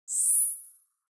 دانلود صدای ربات 58 از ساعد نیوز با لینک مستقیم و کیفیت بالا
جلوه های صوتی